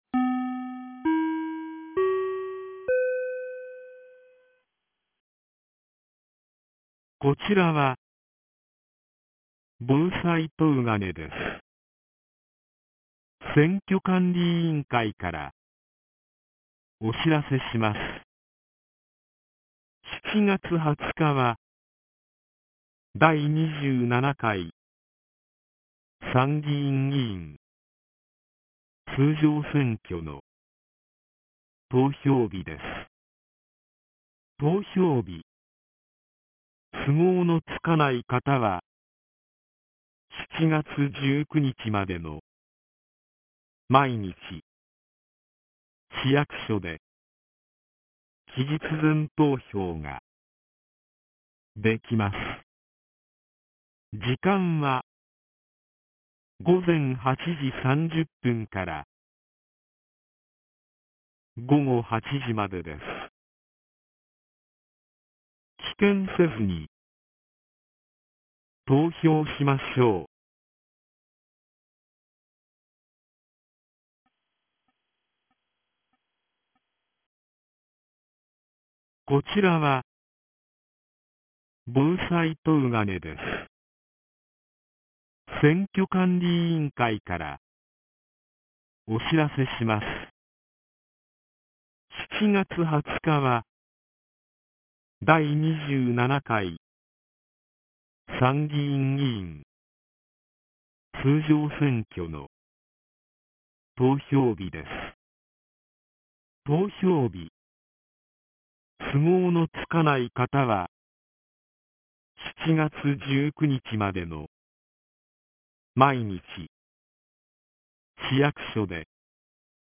2025年07月05日 14時02分に、東金市より防災行政無線の放送を行いました。